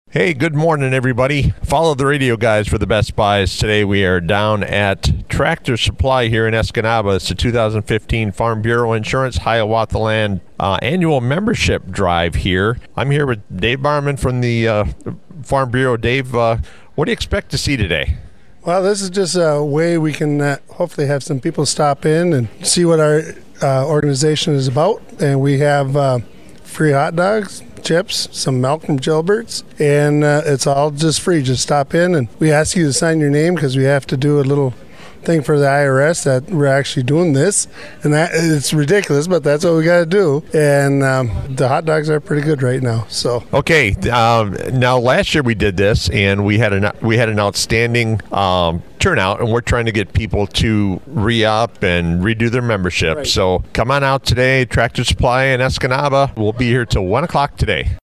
It looked to be a hectic day, as more and more people flooded into the Escanaba Tractor Supply Company’s showroom!